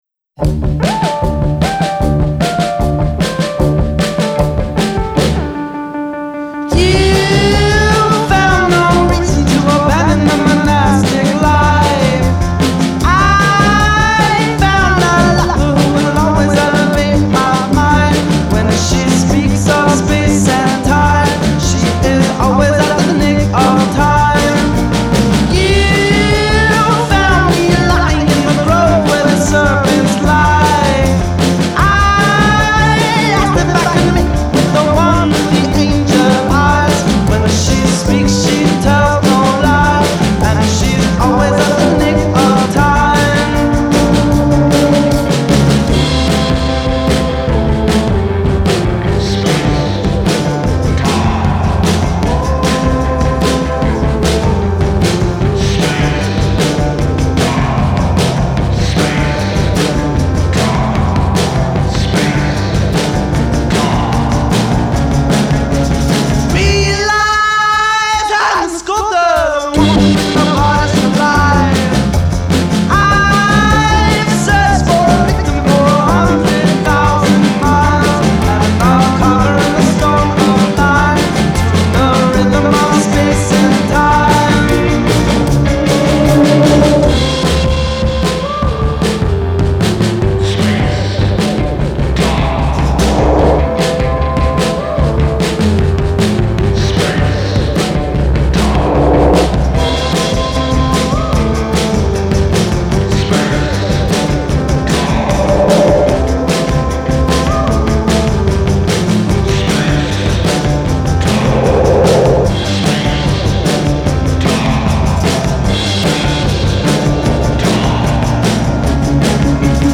slightly psychedelic update on 60s west-coast Americana